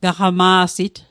Pronunciation Guide: ga·hga·maa·sit